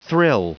Prononciation du mot : thrill
thrill.wav